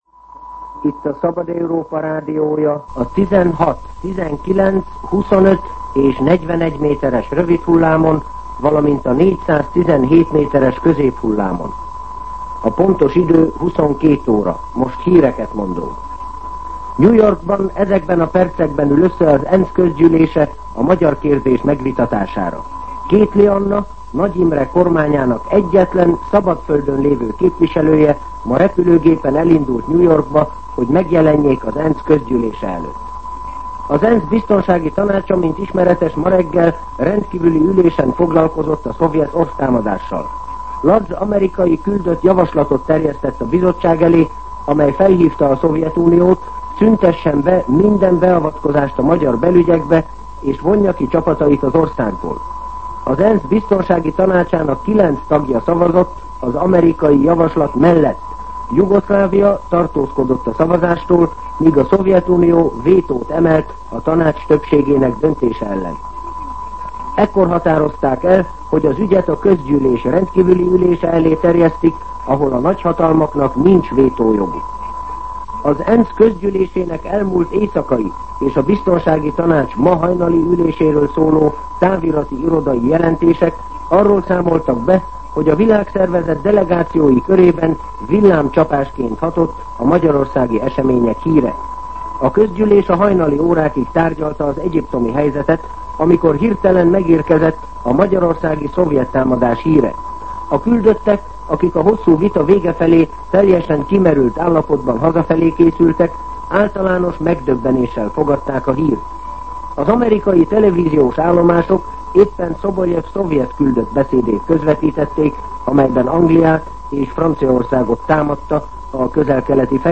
22:00 óra. Hírszolgálat